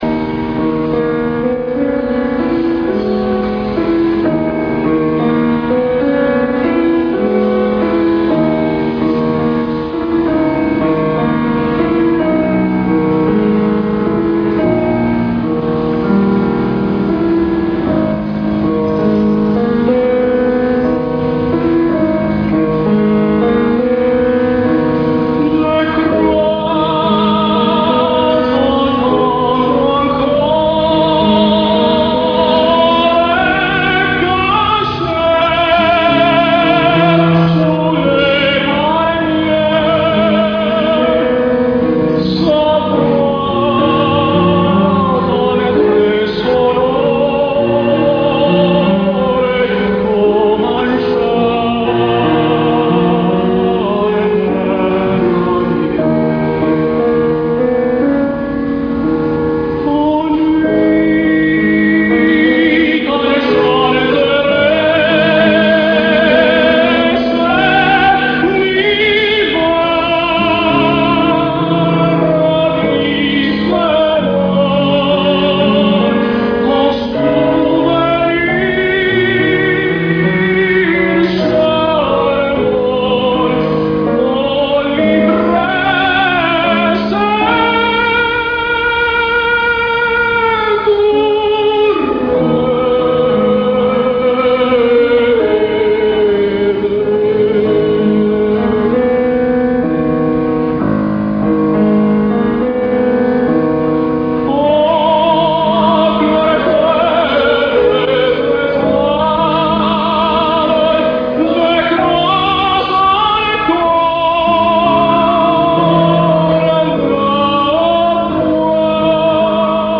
Marcelo Álvarez sings Les pêcheurs de perles:
He was not exactly an exciting interpreter (neither musically nor as an actor), but vocally much superior to most other tenors of his generation.